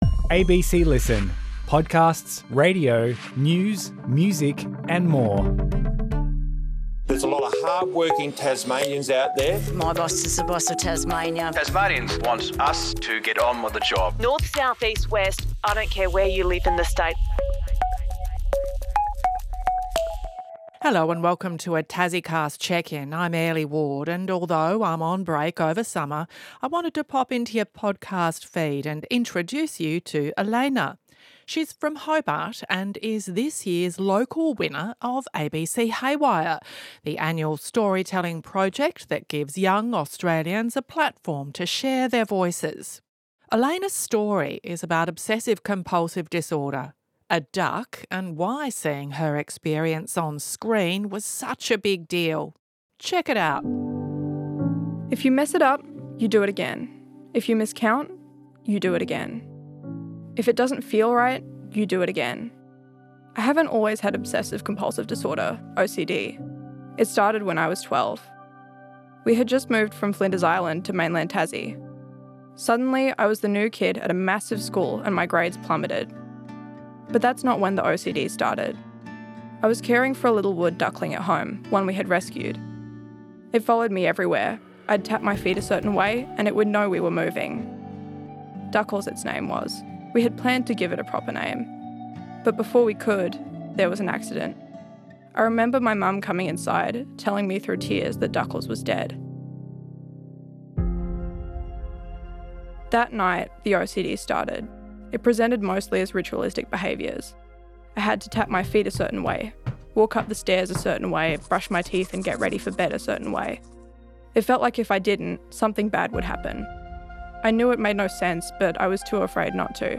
chats with Tasmania's top journos to unpack one of the big stories of the week. If it's going to hit your heart, head or your hip pocket – we're going to break it down for you.